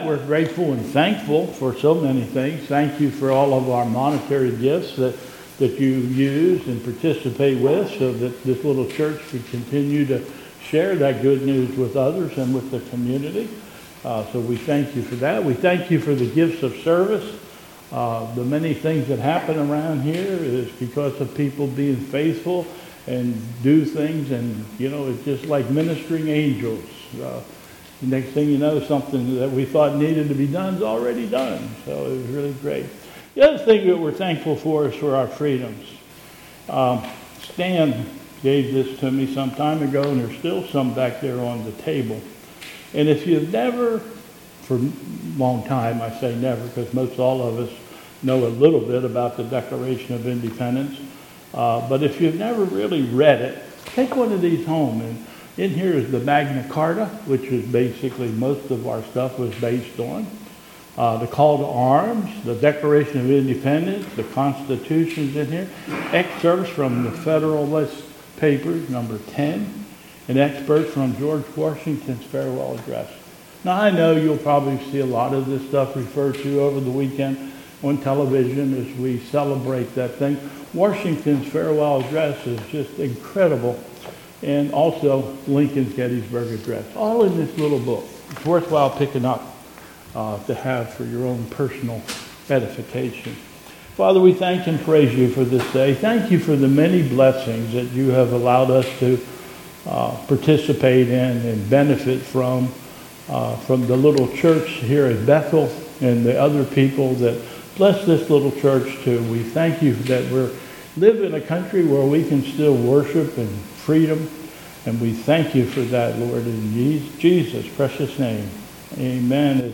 2022 Bethel Covid Time Service
Hymn: "Battle Hymn of the Republic"
Call to Worship